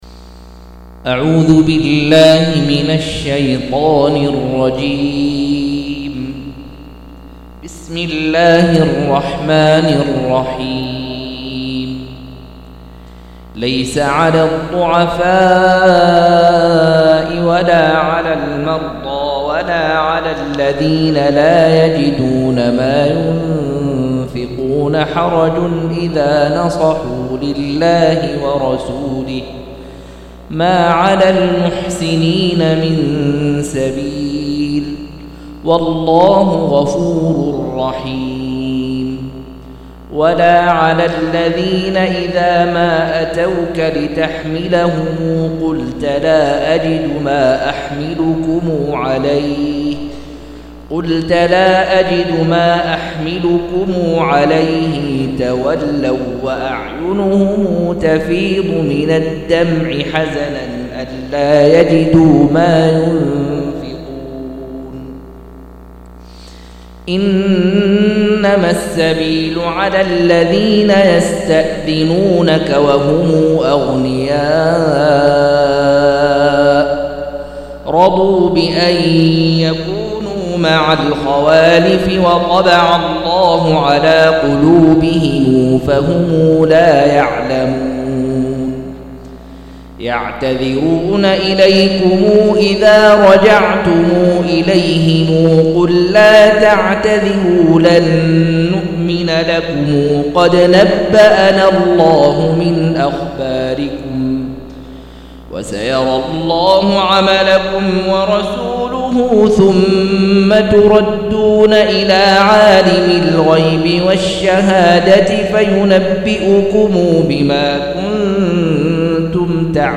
191- عمدة التفسير عن الحافظ ابن كثير رحمه الله للعلامة أحمد شاكر رحمه الله – قراءة وتعليق –